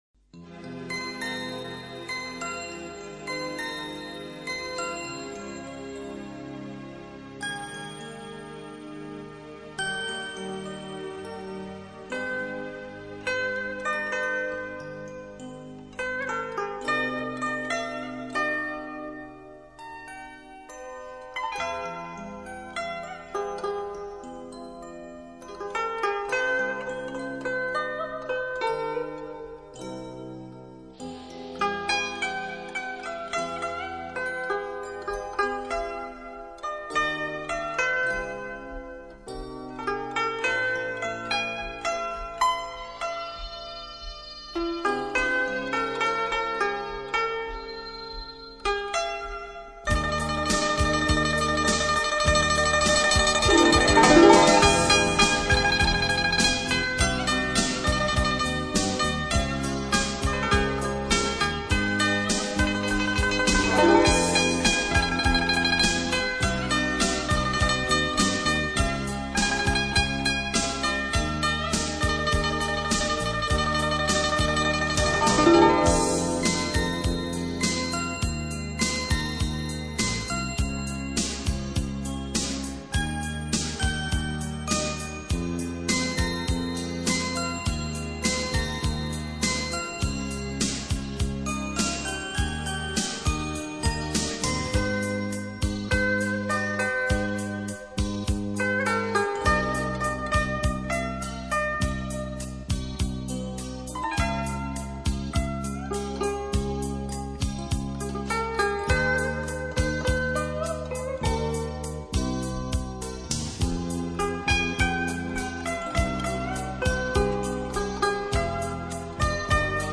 ·所属分类：音乐:轻音乐